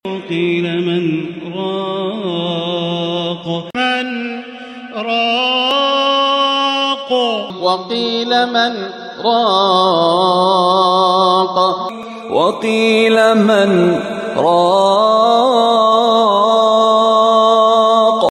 Sakt/Saktah is a pause in recitation by cutting the tune of the voice and no breathing in before continuing with the next word. This occurs in four places according to the narration of Hafs ‘An ‘Asim.